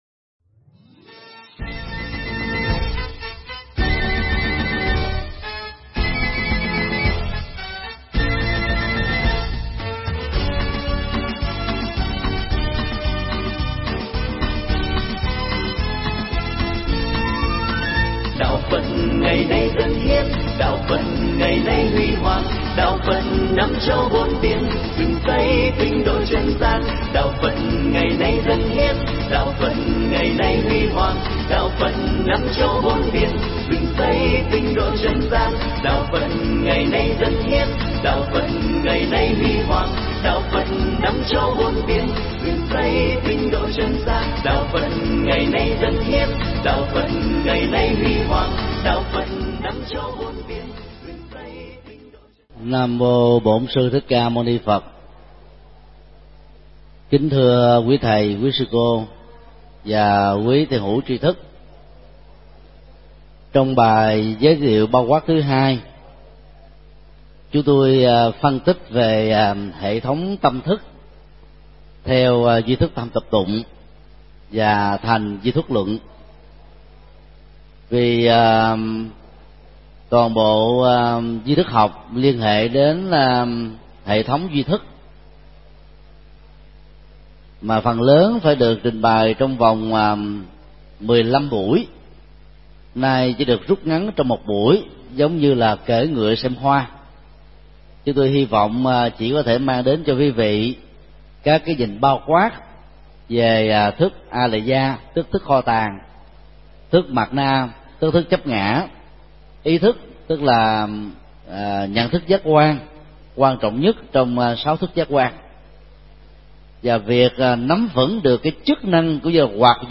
Giảng tại Học viện Phật giáo Việt Nam tại TP. HCM